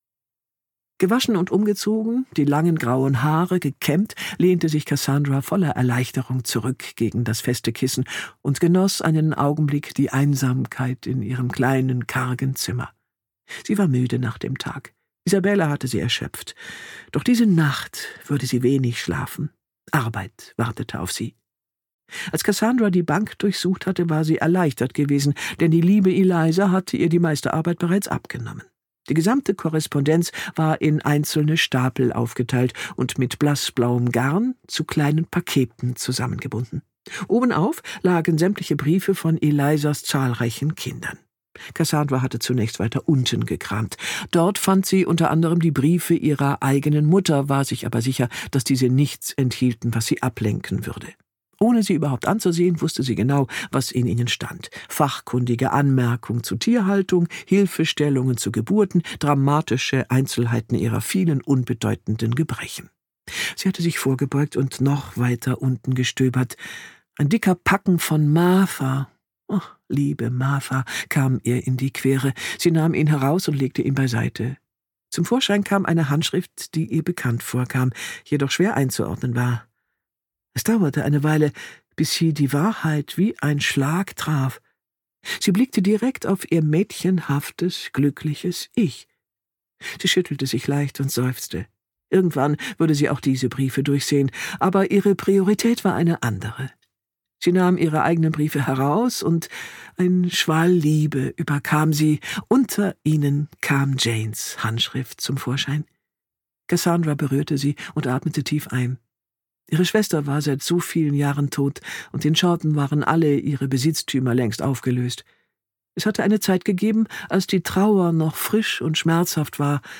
Miss Austen - Gill Hornby | argon hörbuch
Gekürzt Autorisierte, d.h. von Autor:innen und / oder Verlagen freigegebene, bearbeitete Fassung.